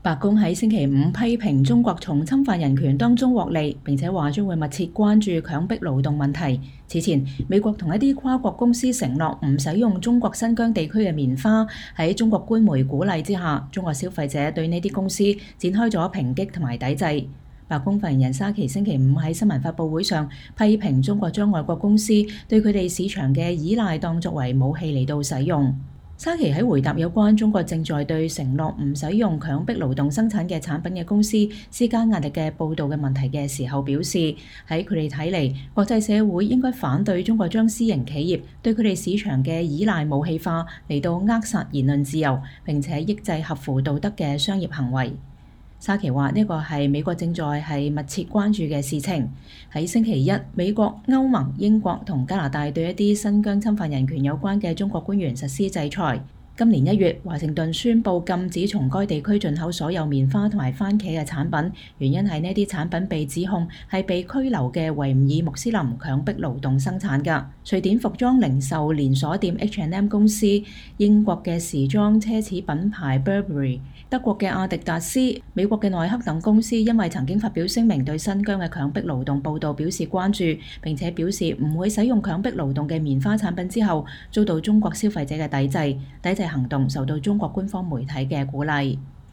白宮發言人莎琪在白宮簡報會上回答記者提問。
白宮發言人莎琪星期五在新聞發布會上批評中國把外國公司對其市場的依賴當作武器來使用。